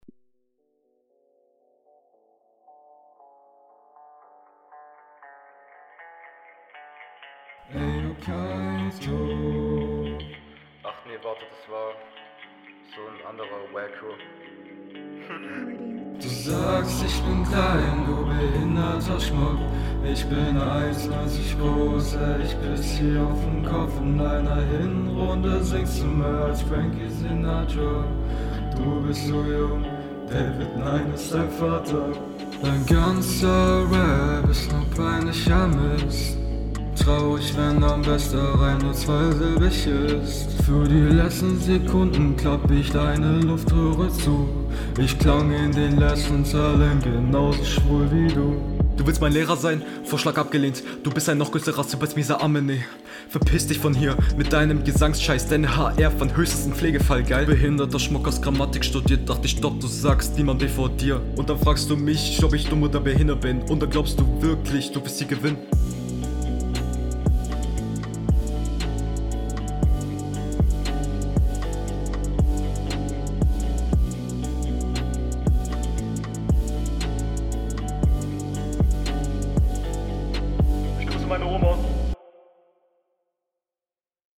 Soundtechnisch ist das leider wieder klar schwächer